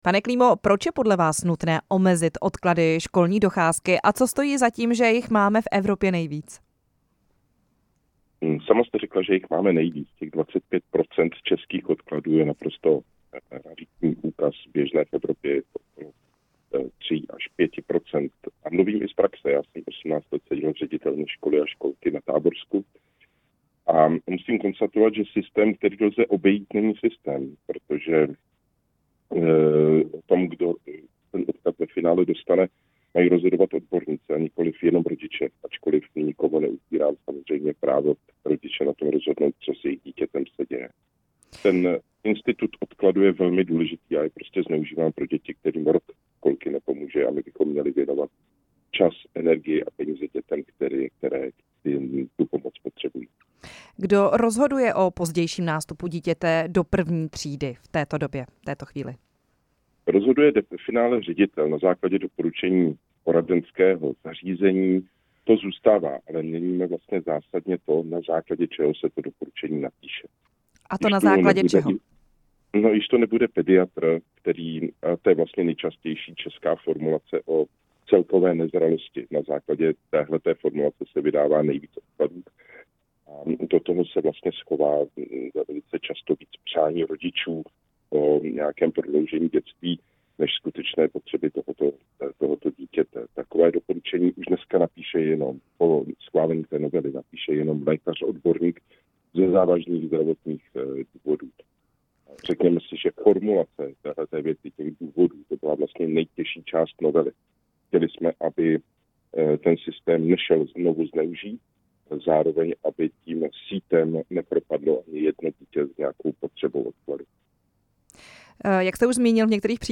To jsme ve vysílání Rádia Prostor probírali s hlavním autorem novely poslancem a pedagogem Pavlem Klímou.
Rozhovor s poslancem a pedagogem Pavlem Klímou